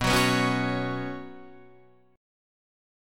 B Suspended 2nd